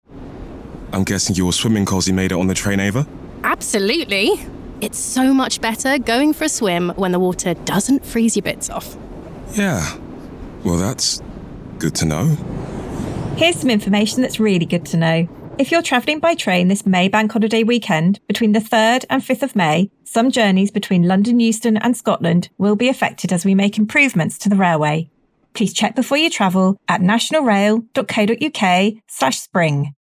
Radio advert